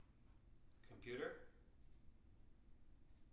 wake-word
tng-computer-334.wav